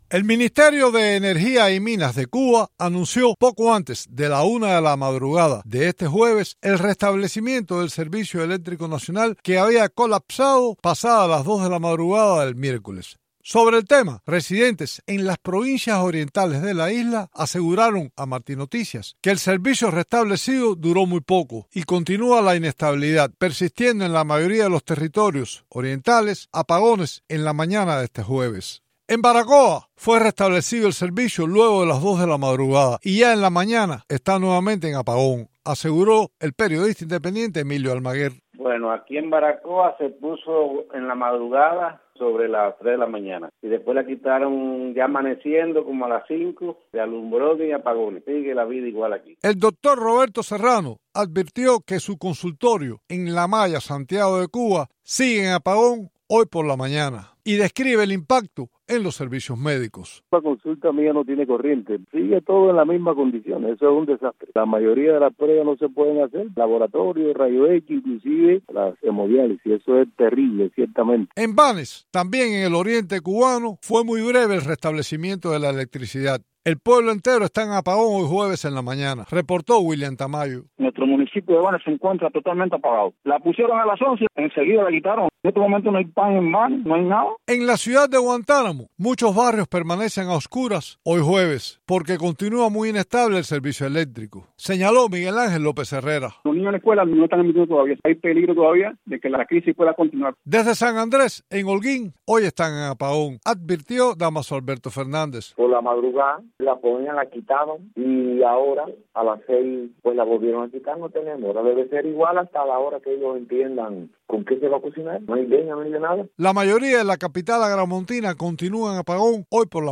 Cubanos residentes en las provincias orientales aseguran que el servicio es muy inestable. "No tenemos con qué cocinar, ni leña hay", dijo uno de los entrevistados a Martí Noticias.